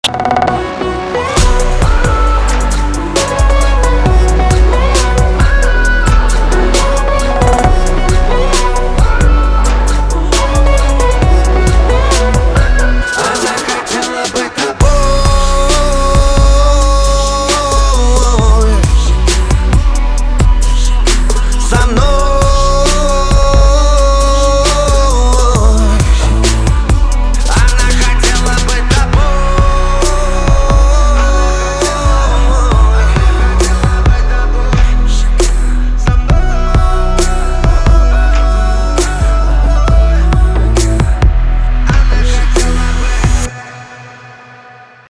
• Качество: 320, Stereo
мужской вокал
dance